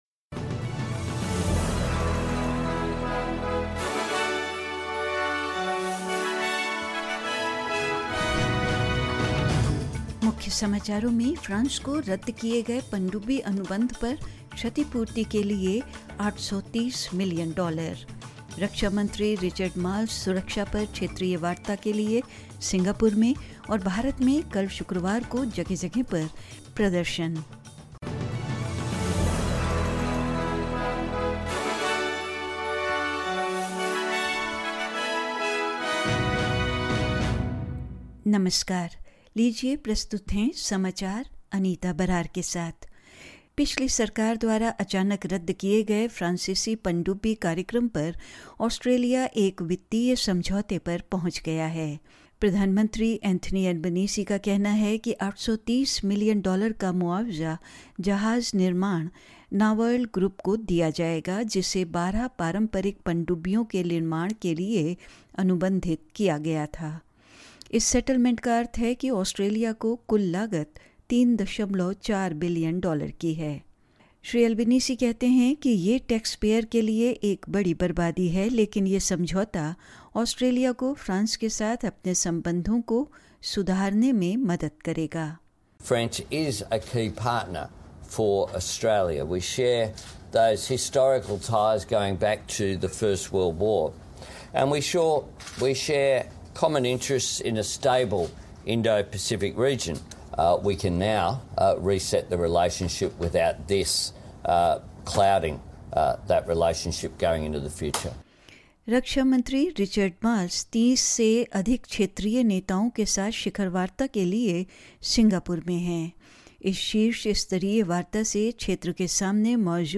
In this latest SBS Hindi bulletin: $830 million to compensate the French for the cancelled submarine contract; Defence minister Richard Marles in Singapore for regional talks on security; In India, protests turned violent post Friday prayers in several parts of India over the Prophet remark controversy and more news.